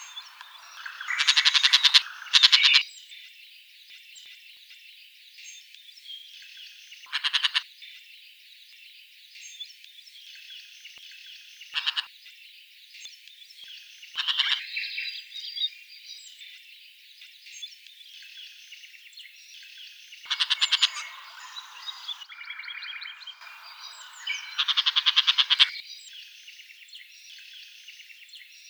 Lanius senator - Woodchat shrike - Averla capirossa